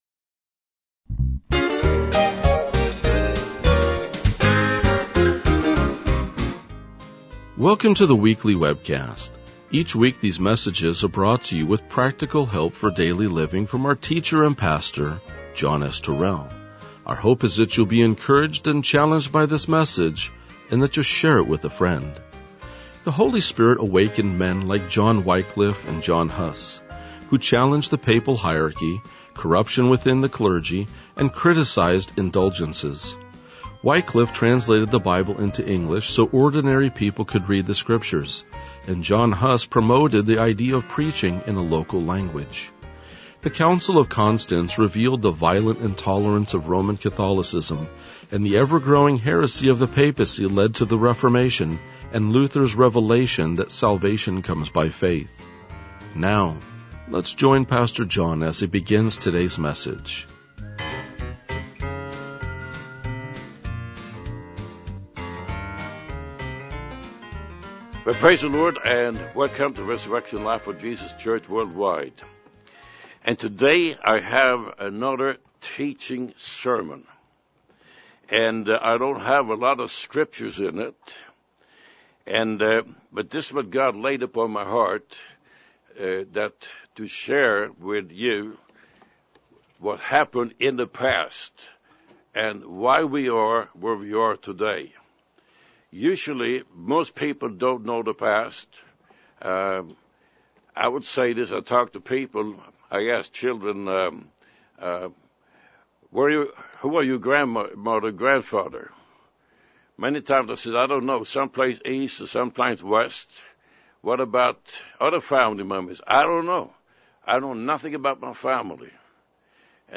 RLJ-2035-Sermon.mp3